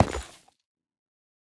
Minecraft Version Minecraft Version latest Latest Release | Latest Snapshot latest / assets / minecraft / sounds / block / nether_ore / step5.ogg Compare With Compare With Latest Release | Latest Snapshot